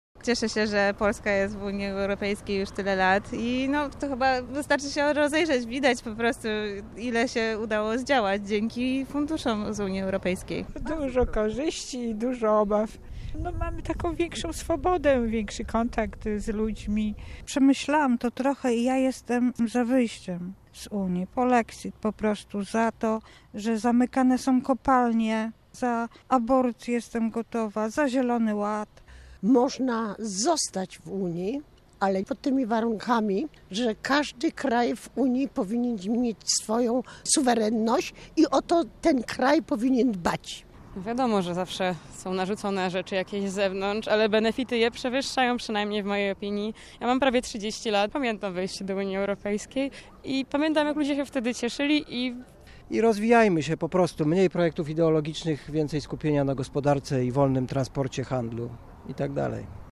Posłuchaj, co Polacy sądzą o członkostwie Polski w UE (IAR)